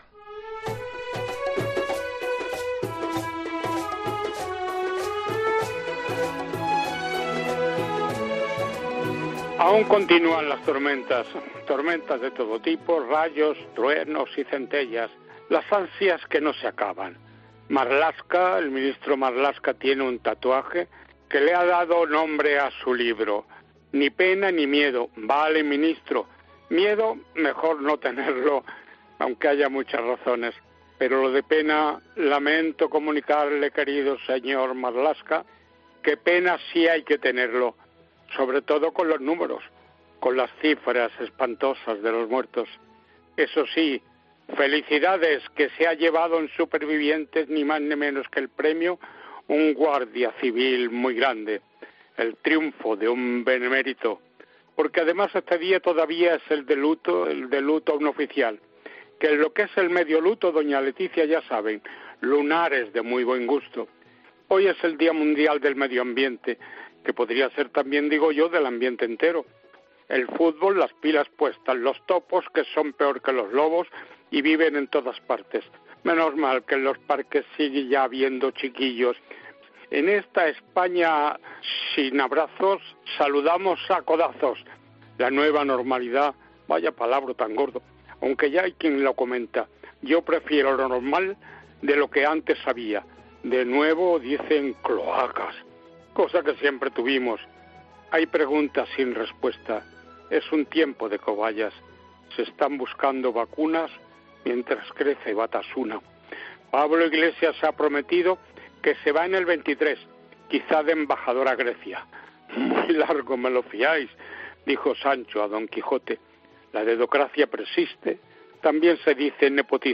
El veterano periodista hace un repaso a la actualidad de la semana en su 'puñaíto'